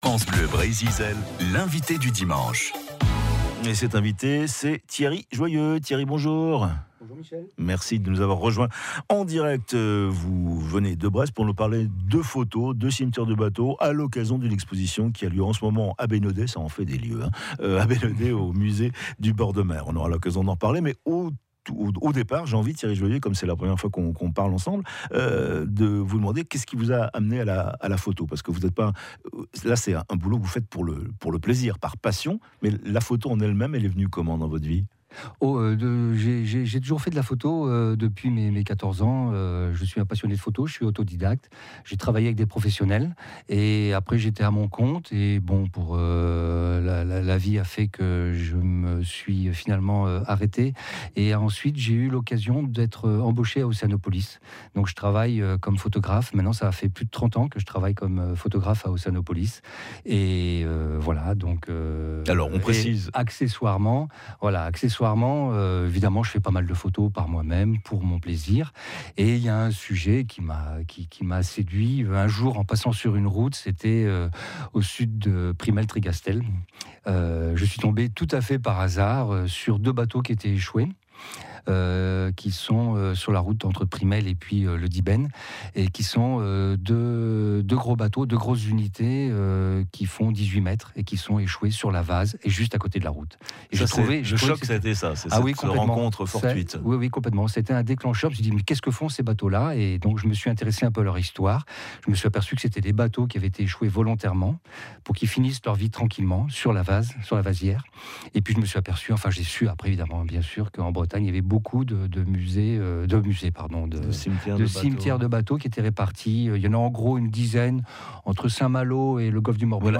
Radio
2 extraits de vidéos et une interview pour en savoir plus !! L'invité du dimanche